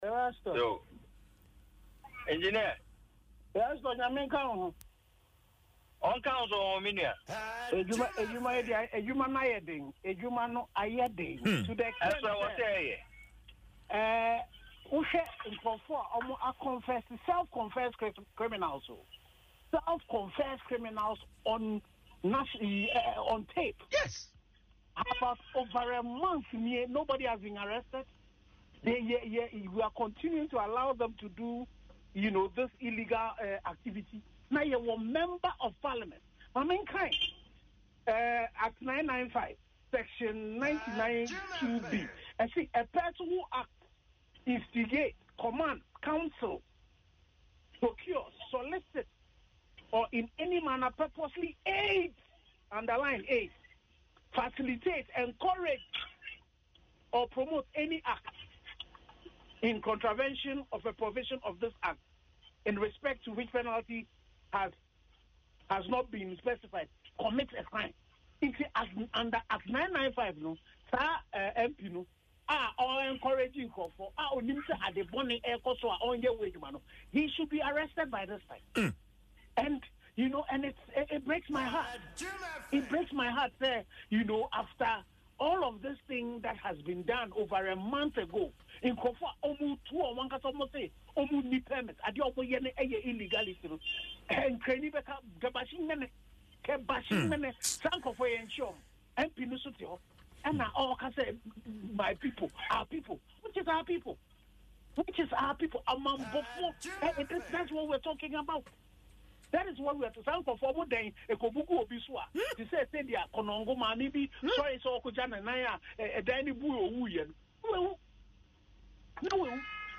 Reacting to the reports in an interview on Adom FM’s Dwaso Nsem